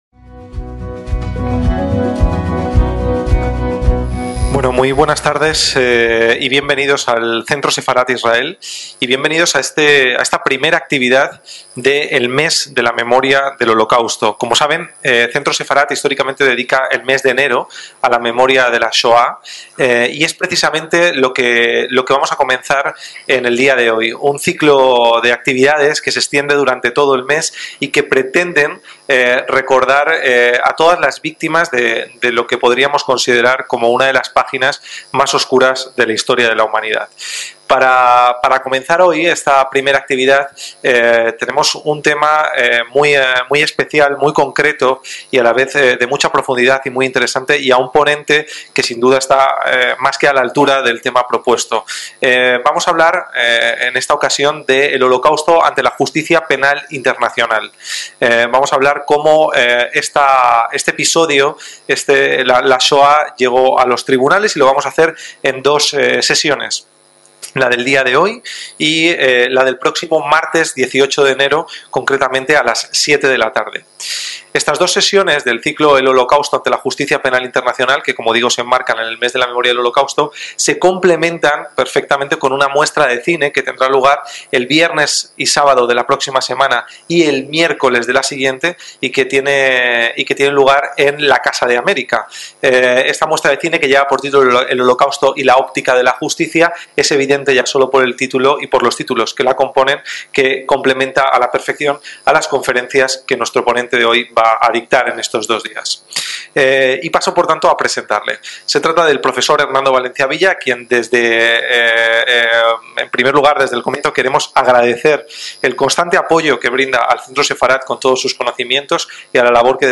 ACTOS EN DIRECTO - Con el final del Holocausto, el Derecho Internacional se enfrentó a uno de los desafíos más importantes de la historia. Los procedimientos judiciales que buscaron tratar la Shoá fueron numerosos y de muy diversa índole.